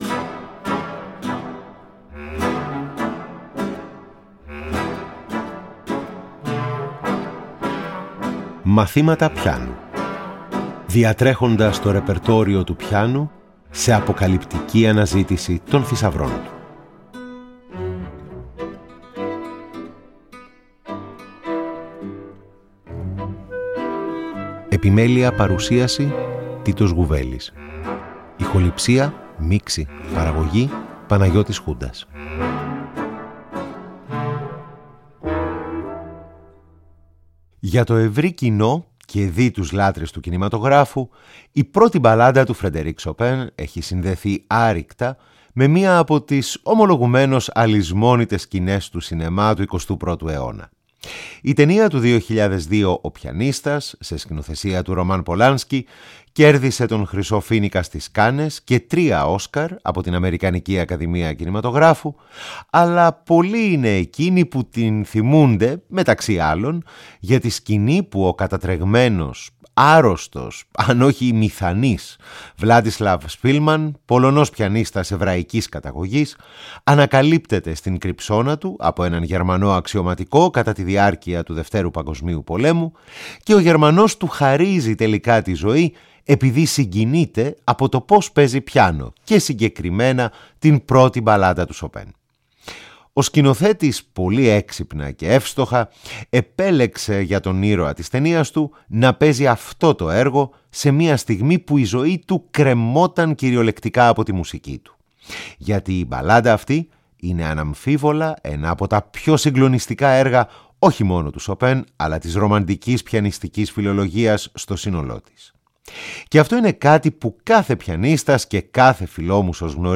Εγχείρημα το οποίο ενισχύει η ενίοτε ζωντανή ερμηνεία χαρακτηριστικών αποσπασμάτων κατά τη διάρκεια της εκπομπής.
Εργα για Πιανο